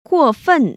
[guò//fèn] 꾸오펀  ▶